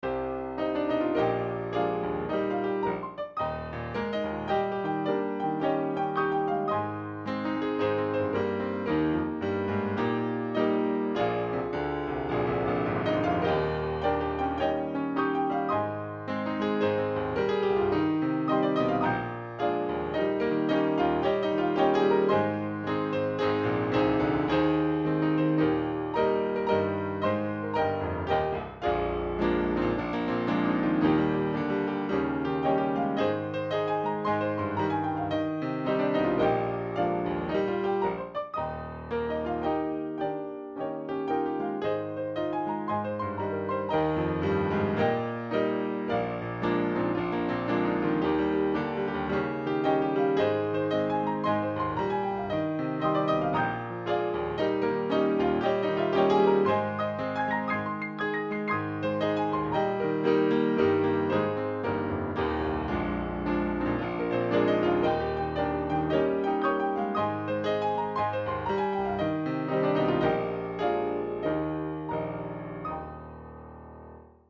Key: E♭